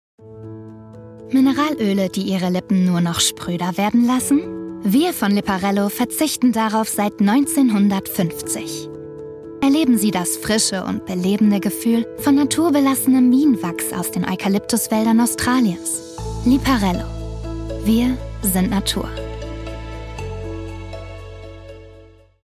Sprecherin für VoiceOver, Synchron & Hörbücher
Meine Stimme fesselt sofort und bleibt im Gedächtnis. Emotional und ausdrucksstark bringe ich deine Werbebotschaft auf den Punkt und mache dein Produkt unvergesslich.
In meinem Homestudio setze ich deine Idee in hochwertige Sprachaufnahmen um.
Demoaufnahme-Werbung.mp3